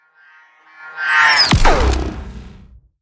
Звуки шаровой молнии
Подборка включает разные варианты звучания, от глухих разрядов до шипящих импульсов.
Ещё так на огромной скорости